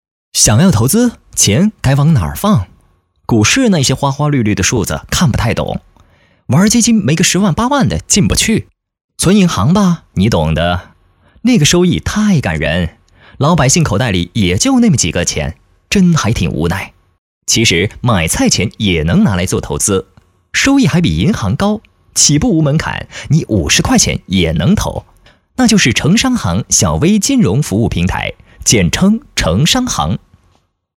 飞碟说男180号（城商行）
年轻时尚 飞碟说配音